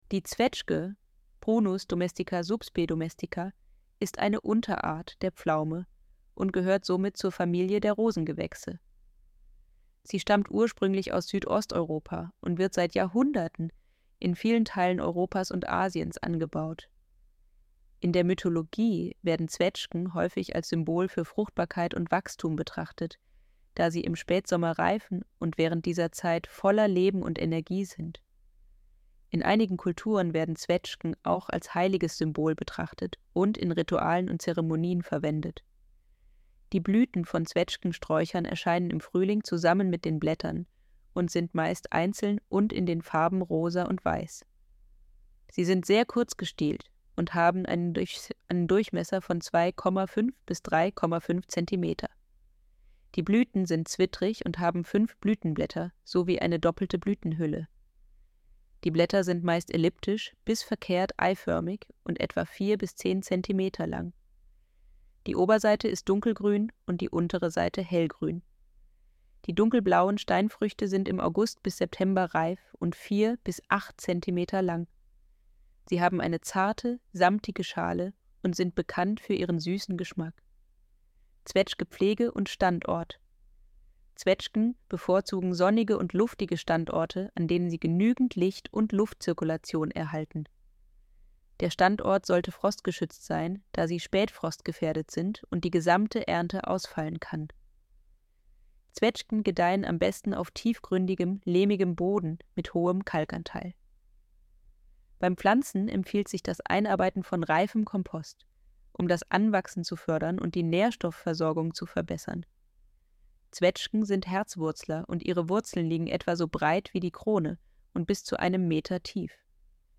Artikel vorlesen